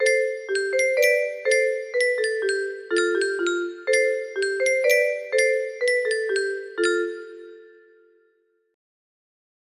just the intro for now